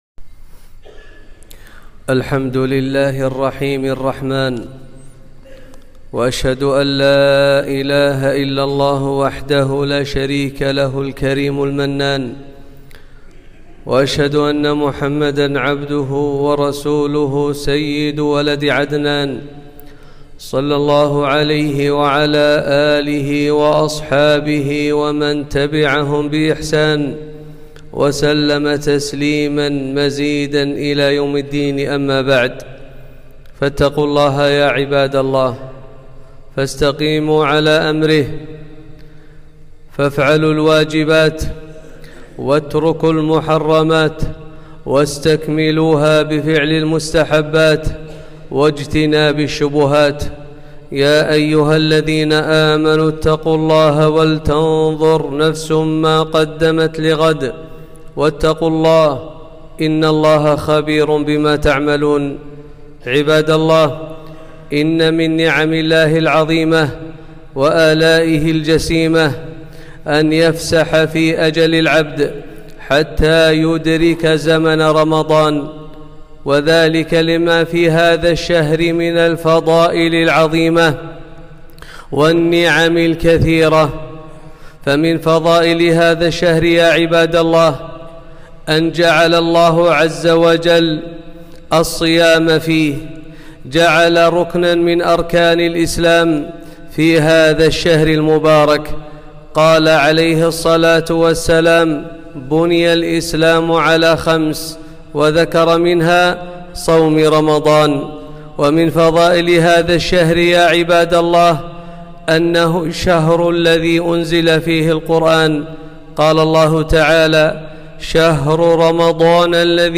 خطبة - فضائل شهر رمضان